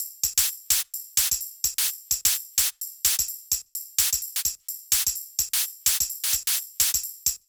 VFH3 Mini Kits Drums